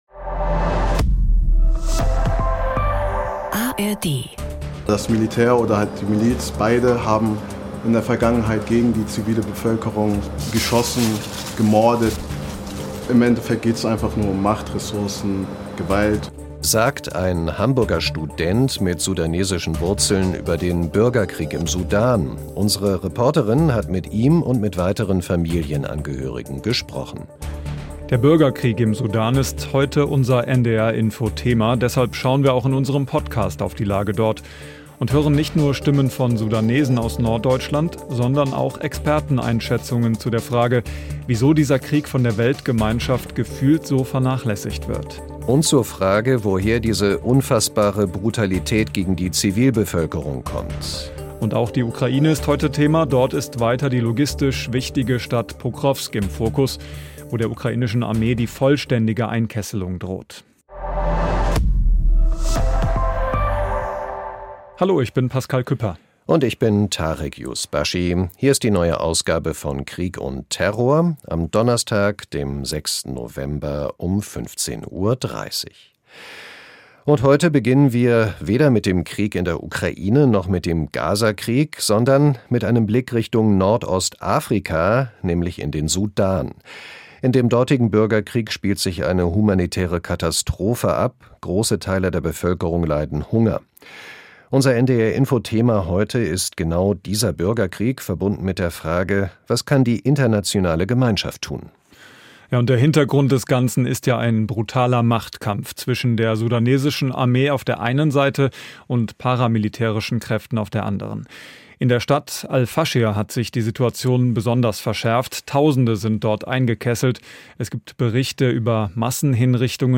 │ Wir hören Expertenstimmen zur Frage, wieso der Krieg gefühlt so vernachlässigt wird und zur Frage, woher die unfassbare Brutalität gegen die unschuldige Zivilbevölkerung kommt │ In Pokrowsk droht der ukrainischen Armee die vollständige Einkesselung durch das russische Militär Mehr